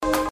Številne dobre in uspešne poslovne ideje spreminjajo lokalno okolje. Nekaj uspelih idej, podjetniških zgodb, ki so zaživele in se razvijajo, je naštela v pogovoru.